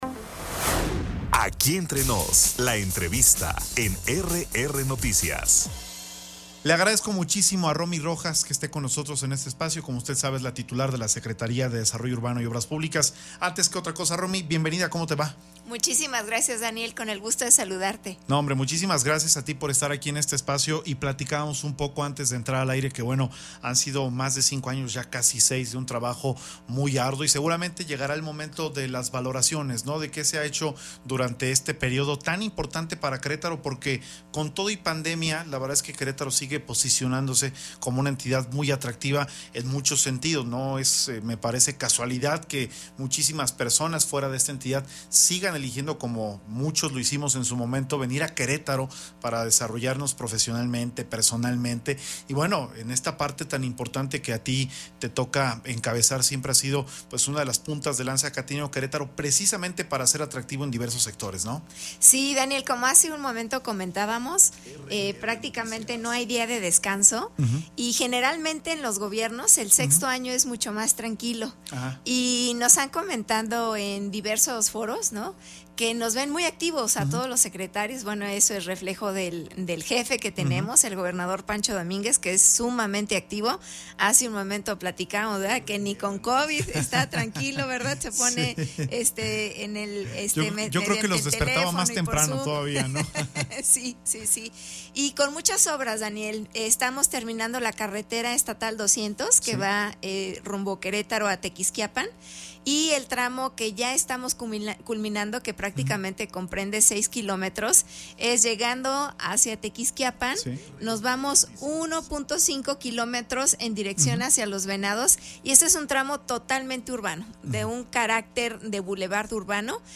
Entrevista con la titular de la SDUOP Romy Rojas - RR Noticias